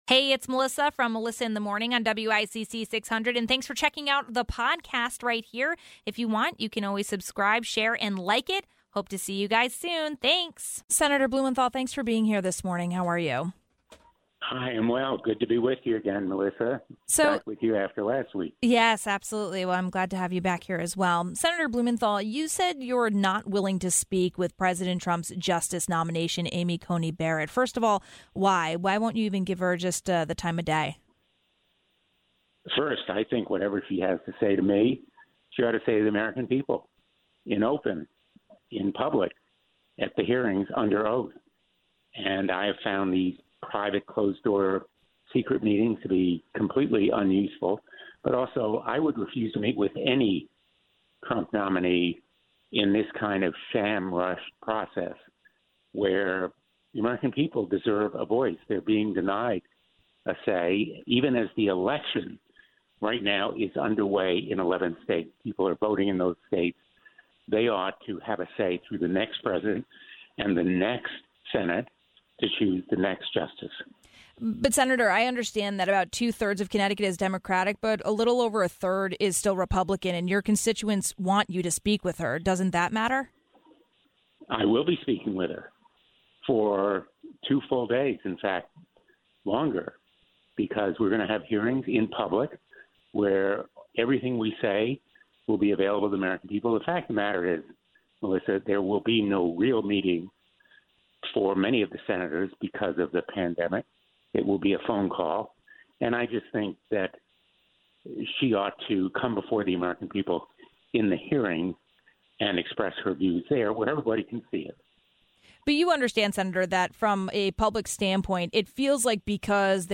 1. Senator Blumenthal addresses the big question: Why won't you meet with Barrett? ((00:11))